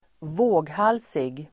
Uttal: [²v'å:ghal:sig]